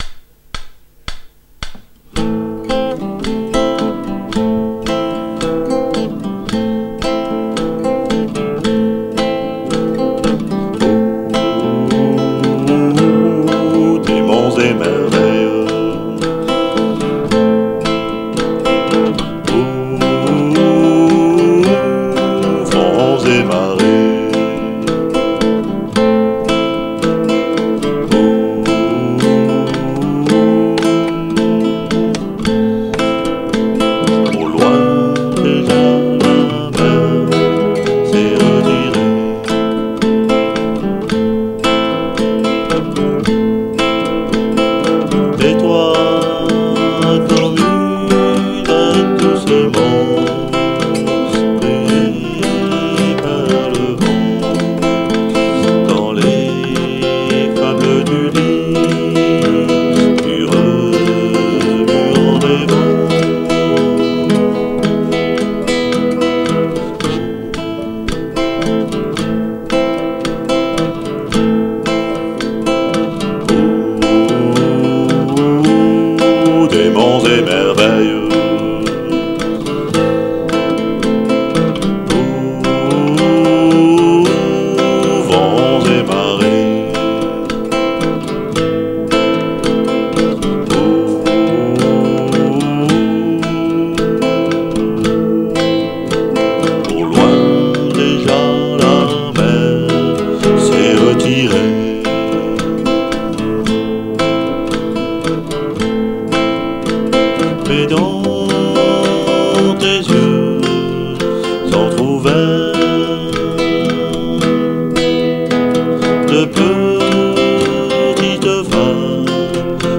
(Quincampoix studio)